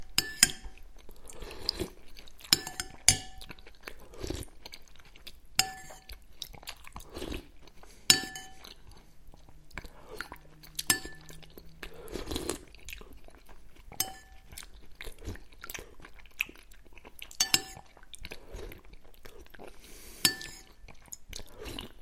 Звук человека, который кушает пельмени с бульоном